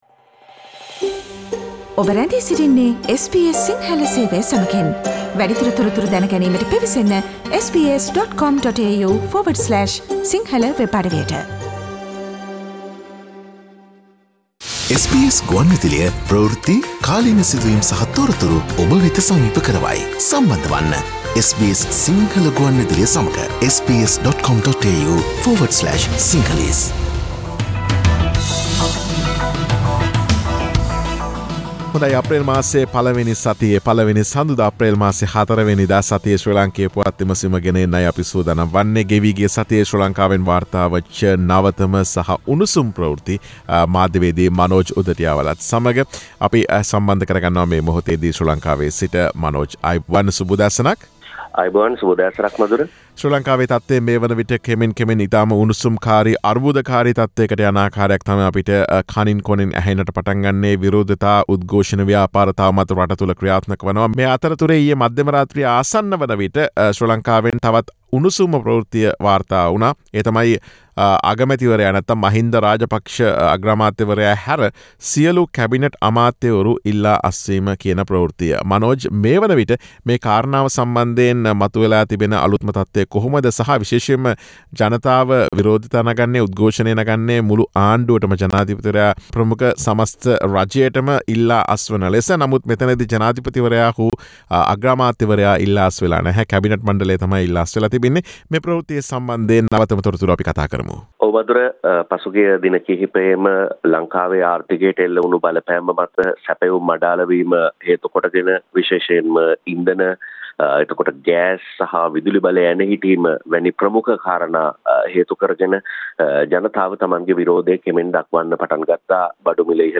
මහින්ද හැර සියලු කැබිනට් ඇමතිවරු ඉල්ලා අස්වෙයි, ගෝටාගේ සහ මහින්දගේ ඊළඟ සැලසුම මොකද්ද: ශ්‍රී ලාංකේය පුවත් විමසුම